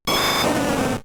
Cri d'Herbizarre K.O. dans Pokémon Diamant et Perle.